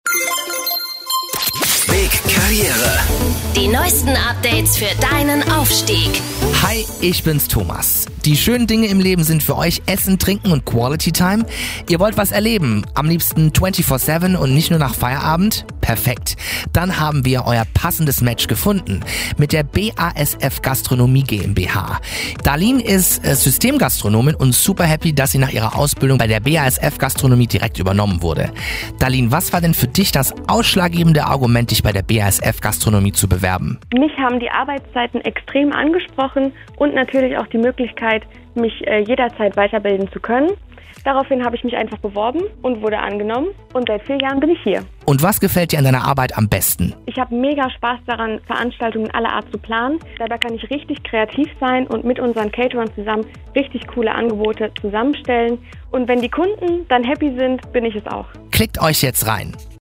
Spots, Infomercials und Infomercials mit O-Tönen (Hörbeispiel) werden auf RPR1. Rhein-Neckar-Pfalz und bigFM Rhein-Neckar ausgestrahlt.
Interview-SALE_BASF_Gastronomie.bigFM_.mp3